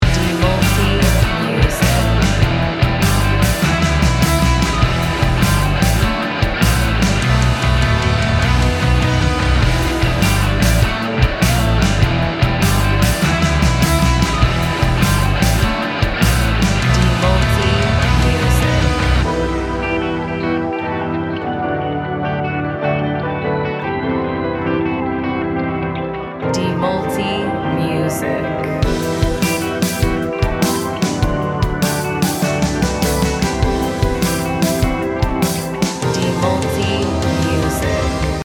Gym Music Instrumental